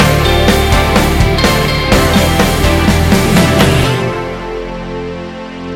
Ionian/Major
D
ambient
electronic
chill out
downtempo
pads
drone